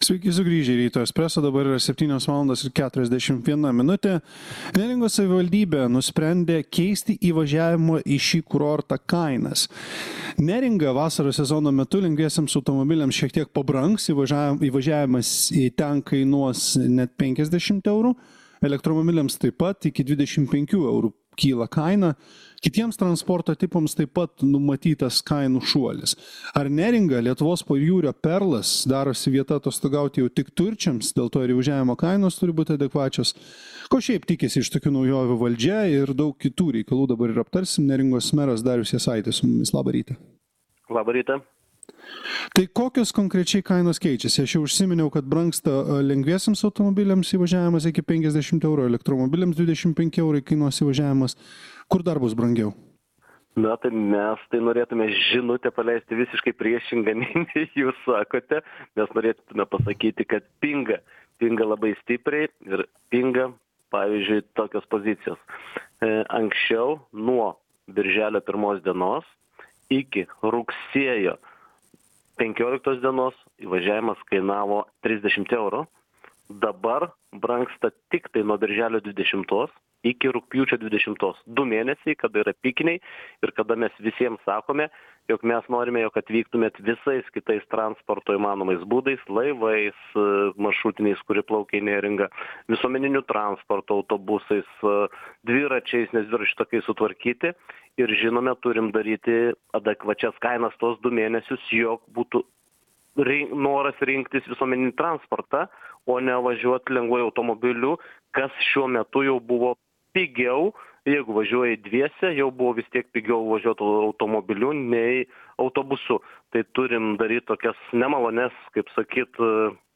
Pokalbis su Neringos meru Dariumi Jasaičiu.